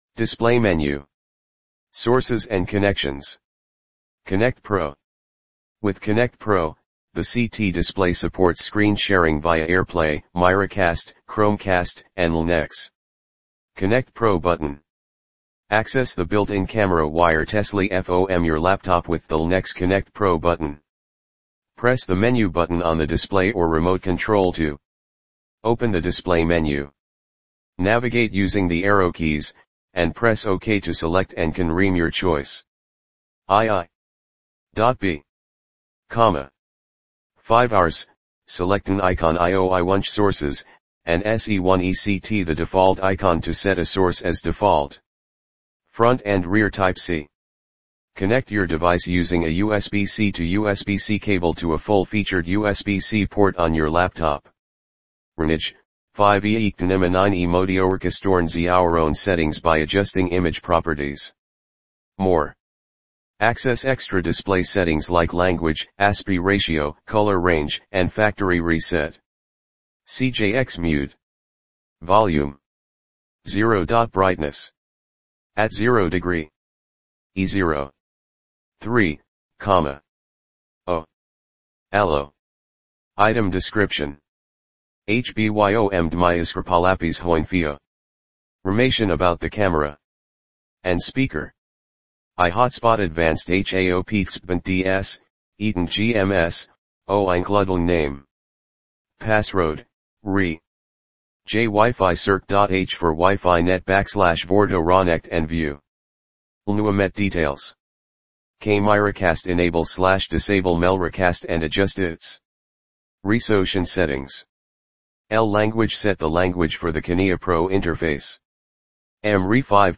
Transform scanned documents and images into audio formats like MP3 or WAV.